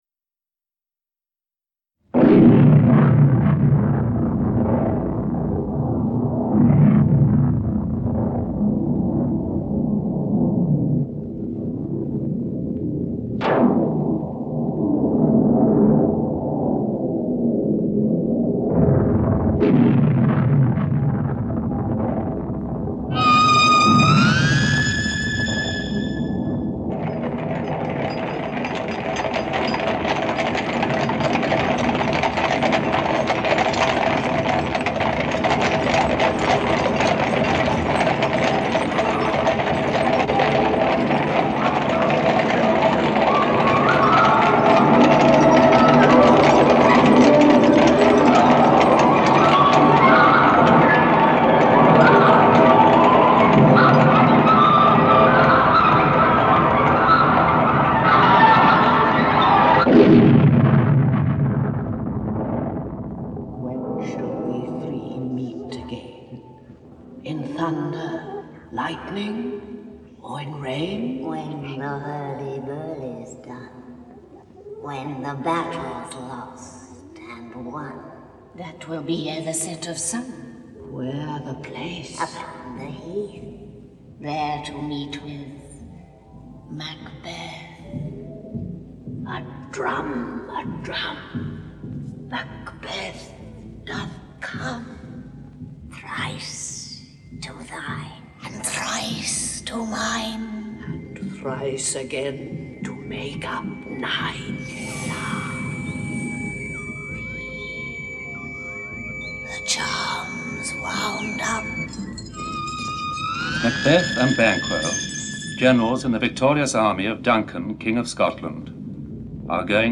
Macbeth (EN) audiokniha
Ukázka z knihy
Adaptation and theatrical version of The Tragedy of Macbeth - a play written by William Shakespeare.